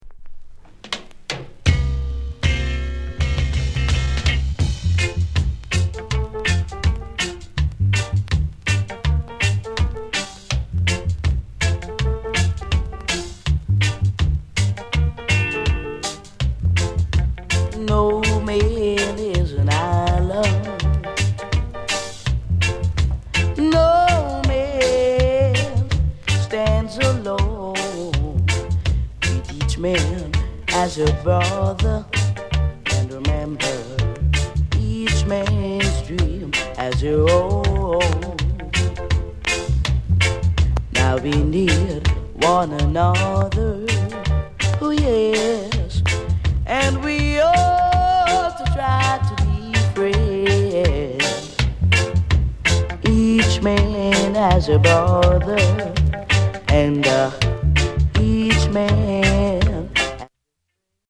SOUND CONDITION A SIDE EX-